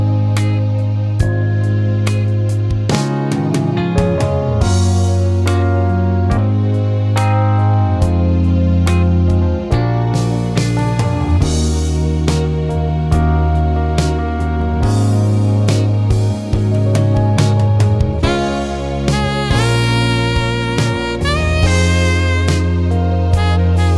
For Duet Duets 3:59 Buy £1.50